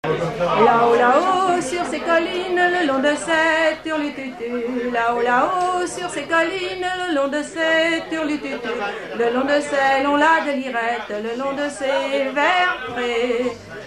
Chanson des environs de Redon
Genre laisse
regroupement de chanteurs à l'écomusée